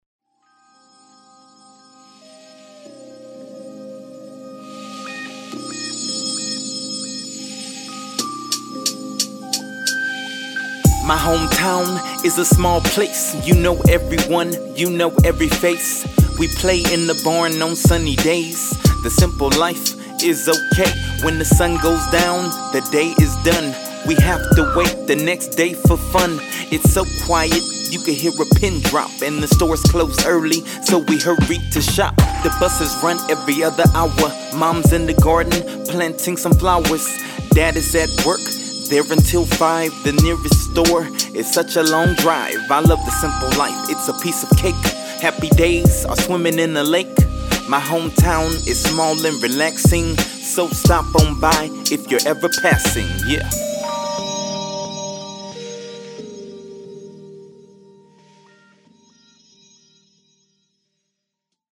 hometown rap song